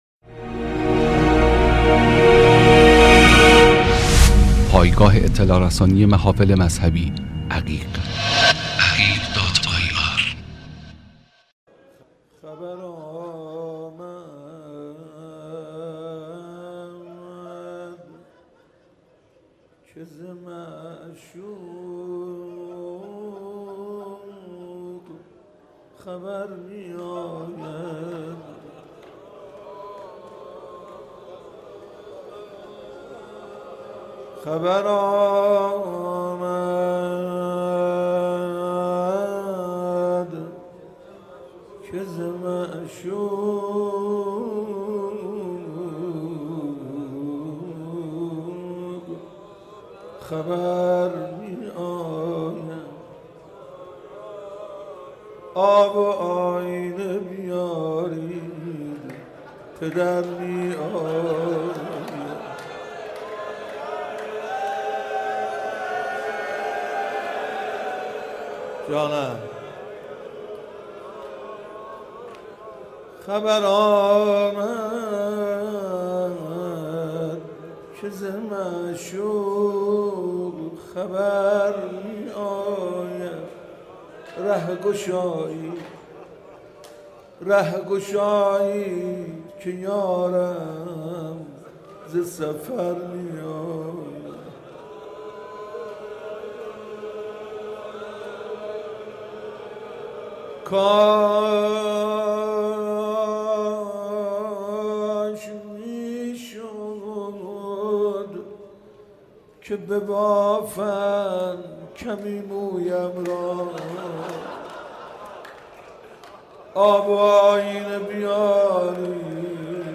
عقیق: مراسم شب اول ماه مبارک رمضان در مسجد ارک با سخنرانی حجت الاسلام میرباقری و مناجات خوانی حاج منصورارضی و حاج حسین سازور برگزار شد.
بخش اول - مناجات دریافت بخش دوم - روضه دریافت بخش سوم - دعا دریافت بخش چهارم - مداحی حاج حسین سازور دریافت